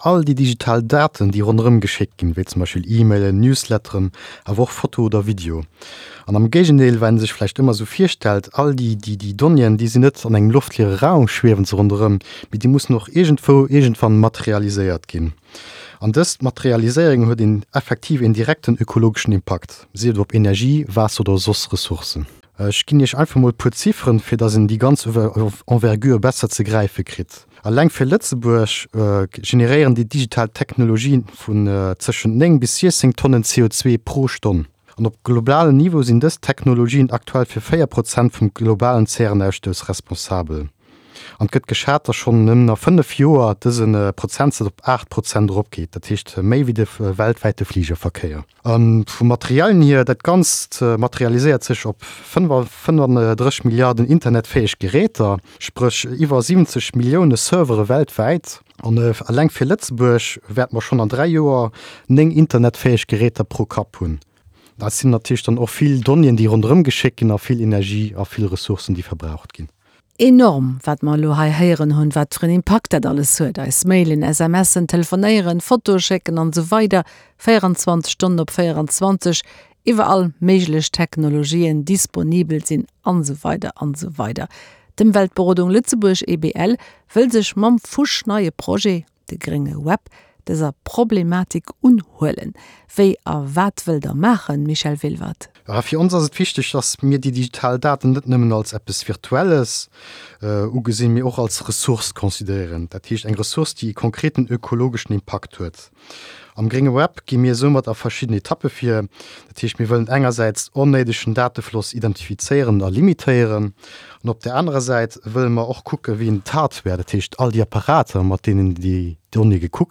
Interview um Radio 100,7 – Grénge Web (09/09/20):